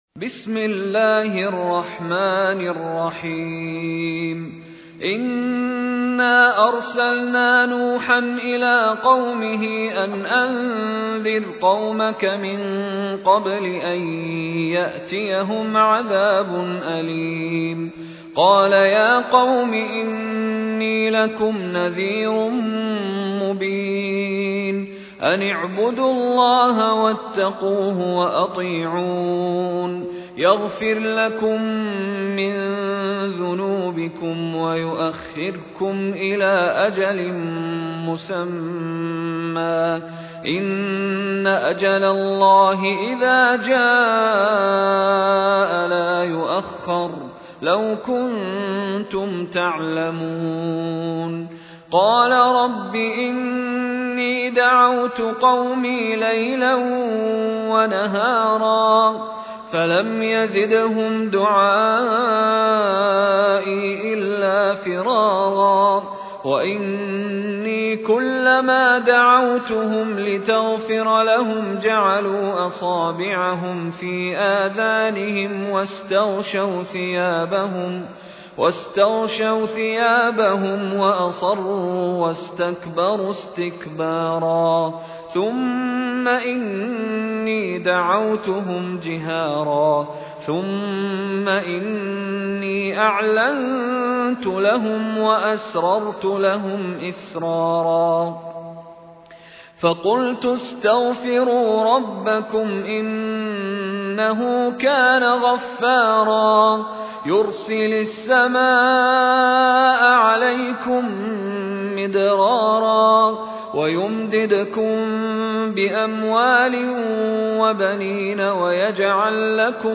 المصاحف - مشاري بن راشد العفاسي
المصحف المرتل - حفص عن عاصم